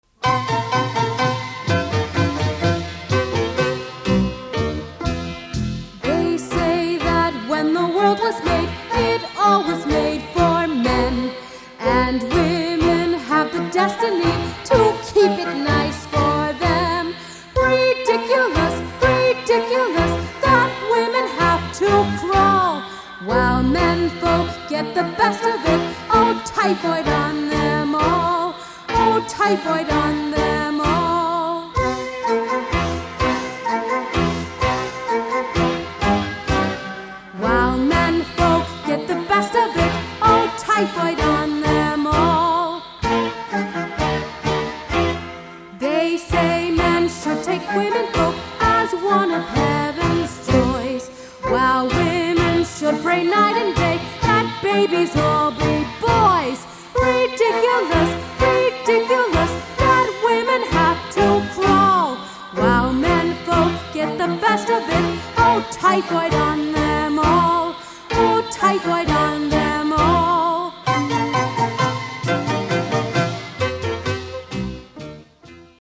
Seoul Olympics Arts Festival '88